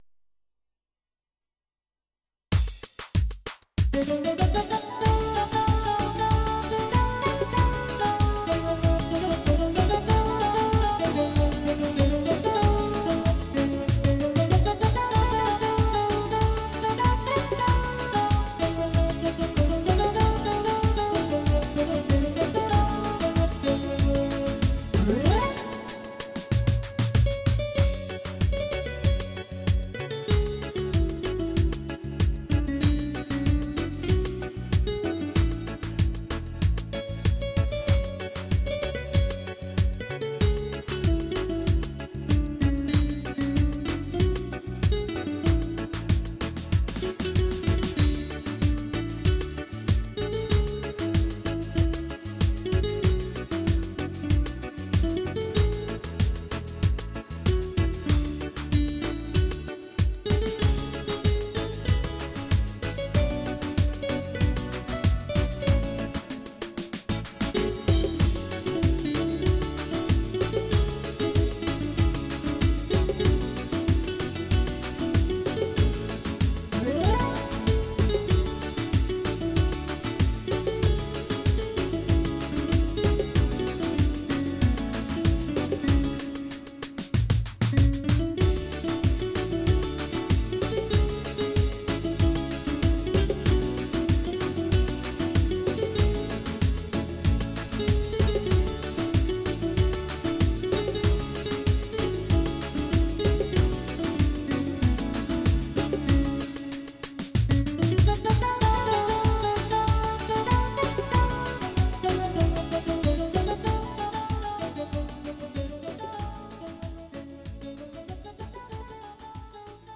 roland gw-8'in fabrika(world) ritimlerinden